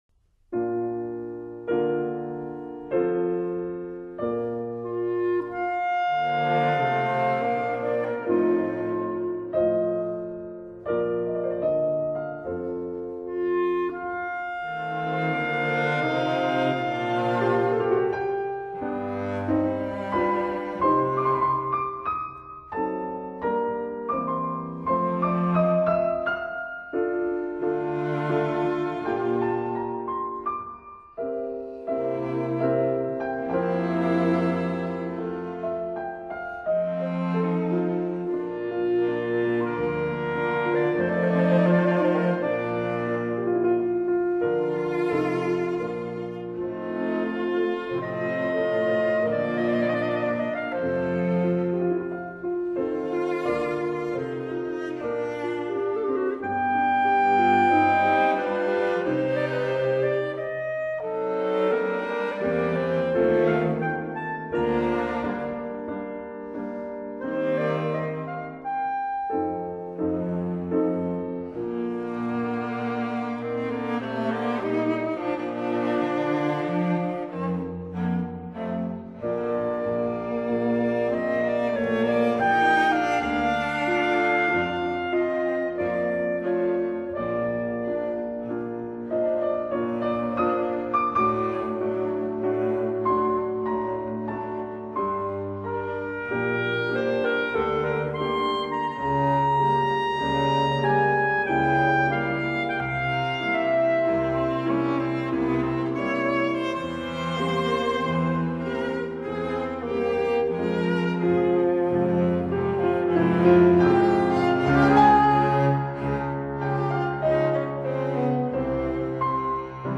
這組重奏是為鋼琴大提琴與單簧管所譜寫，絕妙的組合揉合至高的靈感與美樂，高雅而動人。